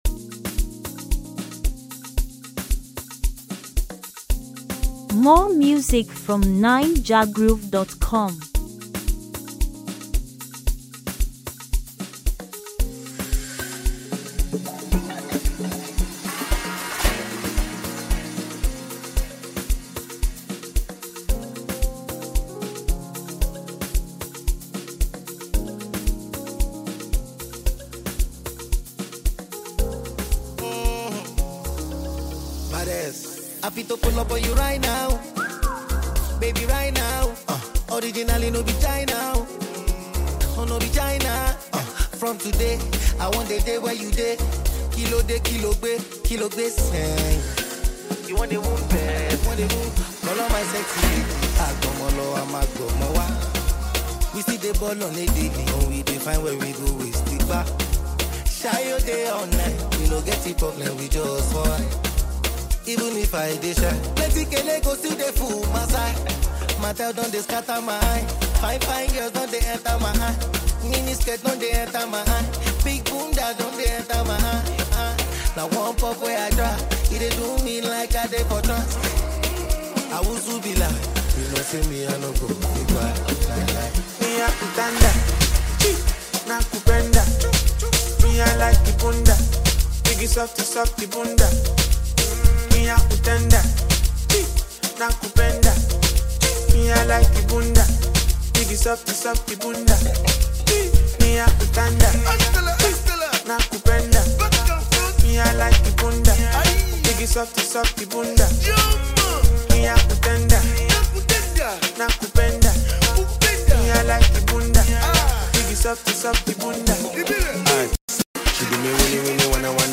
Latest, African-music, Naija-music